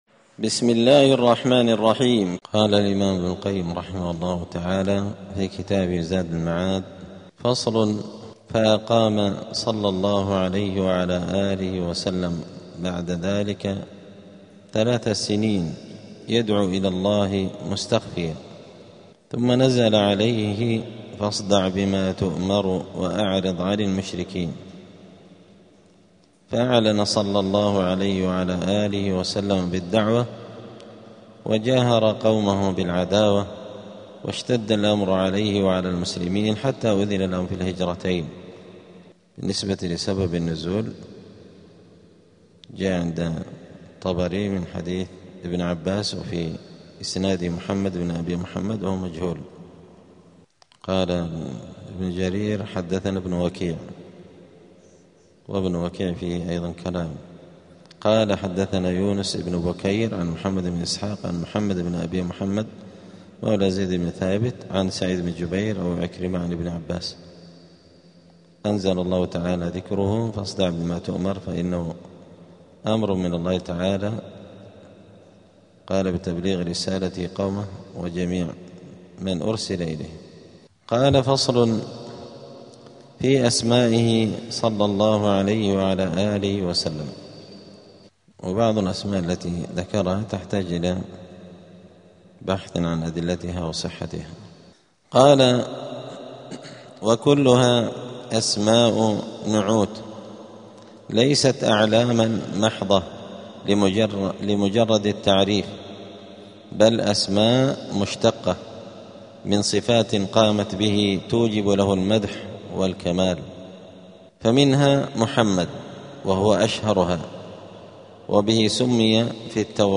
*الدرس الخامس عشر (15) {ﻓﺼﻞ ﻓﻲ ﺃﺳﻤﺎﺋﻪ ﺻﻠﻰ اﻟﻠﻪ ﻋﻠﻴﻪ ﻭﺳﻠﻢ}.*